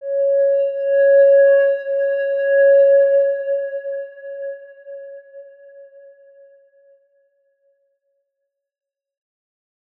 X_Windwistle-C#4-pp.wav